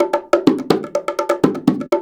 Index of /90_sSampleCDs/Houseworx/02 Percussion Loops